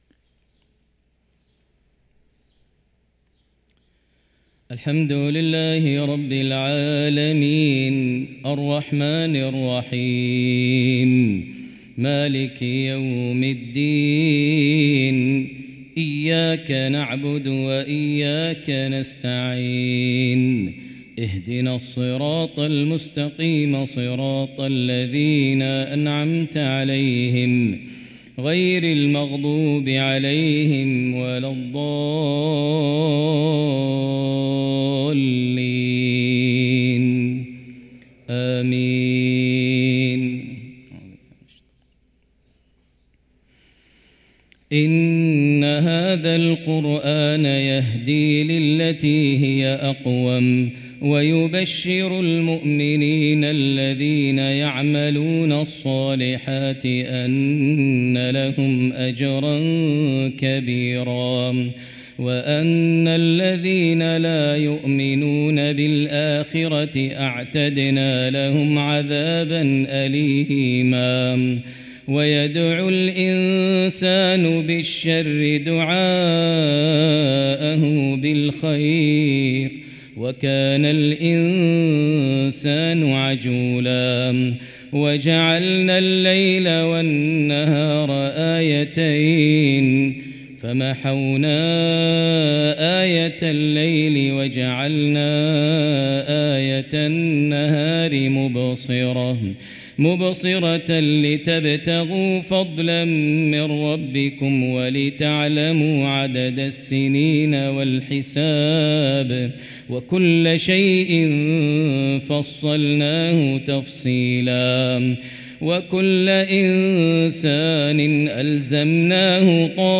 صلاة الفجر للقارئ ماهر المعيقلي 4 رجب 1445 هـ